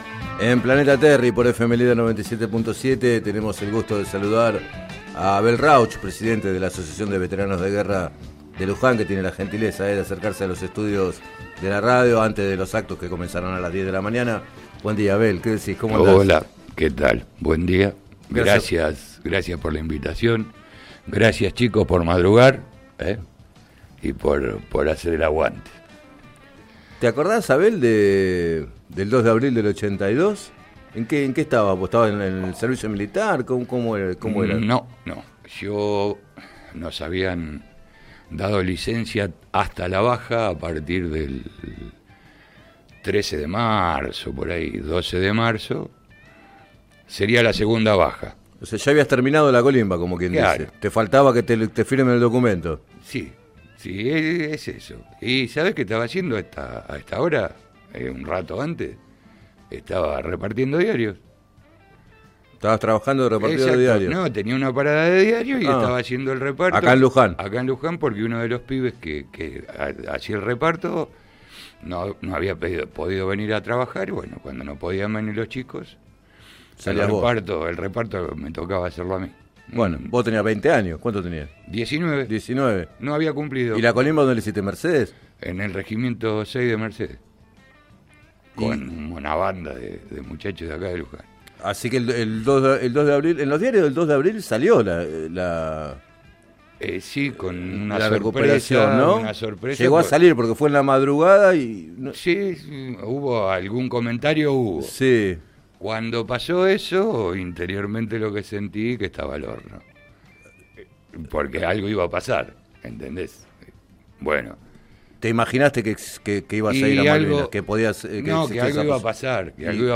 Entrevistado en el programa Planeta Terri de FM Líder 97.7